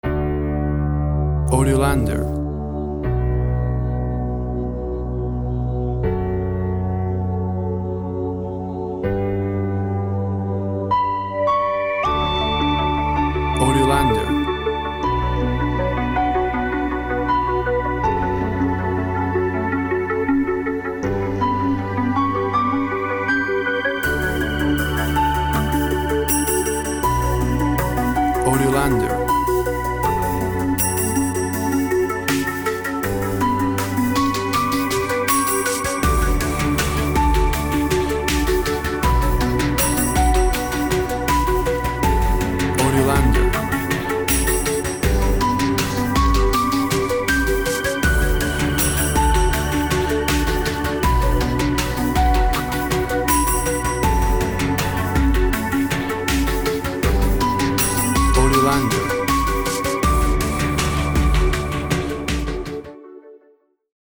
Reflective musical piece based on respect and human dignity.
Tempo (BPM) 80